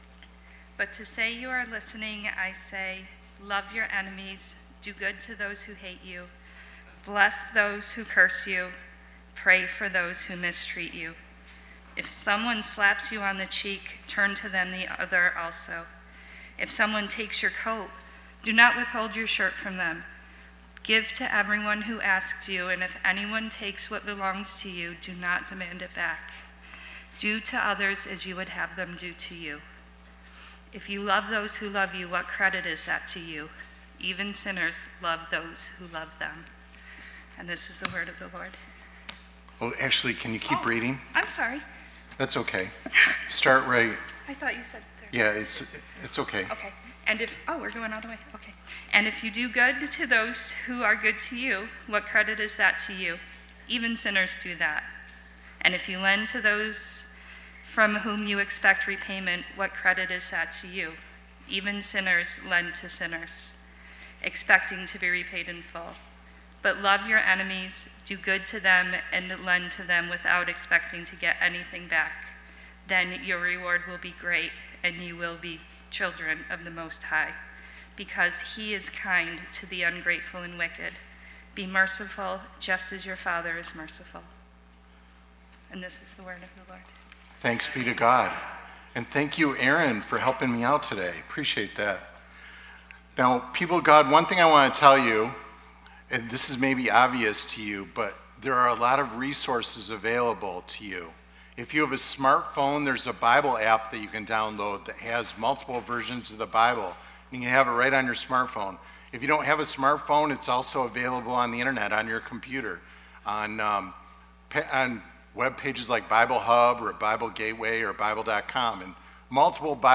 Lakeview Sermon Podcast